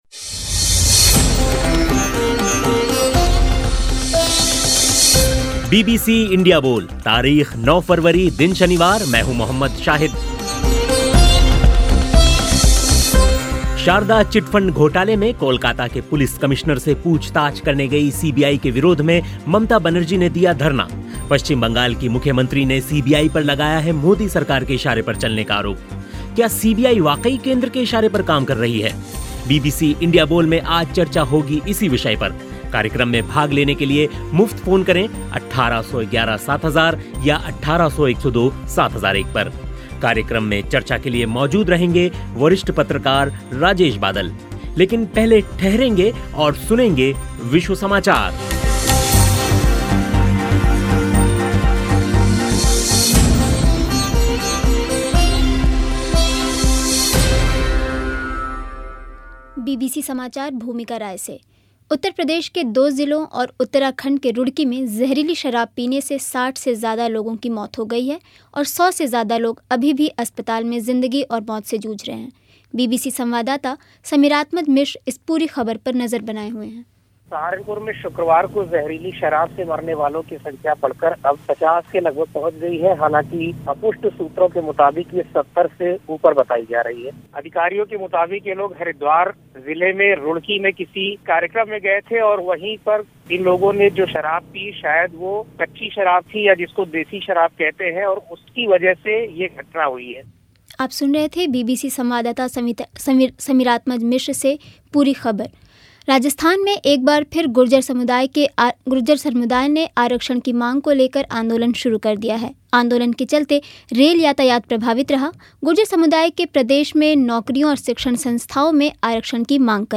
बीबीसी इंडिया बोल में चर्चा इसी विषय पर हुई.